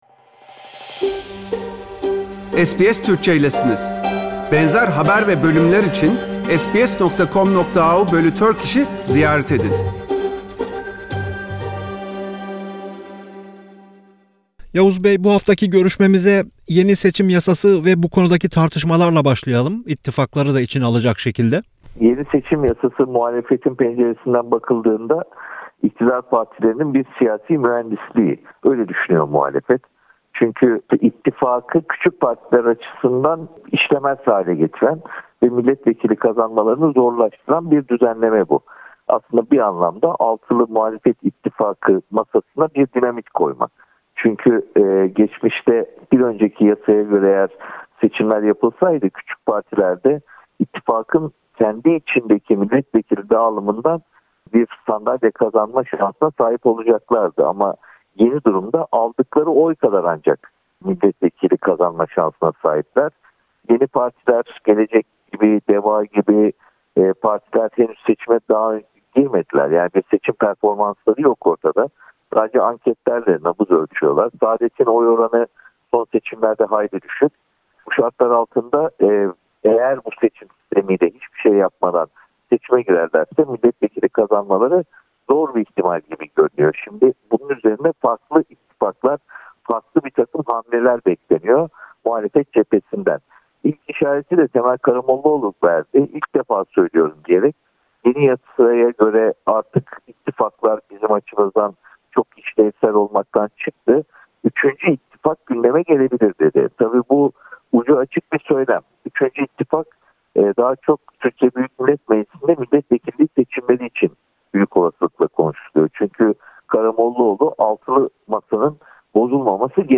Gazeteci Oğhan yeni seçim yasası, resmi faiz oranları ver Süleyman Soylu'nun açıklamaları konusunda sorularımızı yanıtladı.